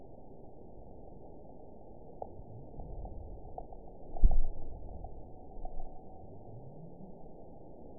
event 922077 date 12/26/24 time 05:27:07 GMT (5 months, 3 weeks ago) score 9.59 location TSS-AB03 detected by nrw target species NRW annotations +NRW Spectrogram: Frequency (kHz) vs. Time (s) audio not available .wav